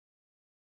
close-window-1.wav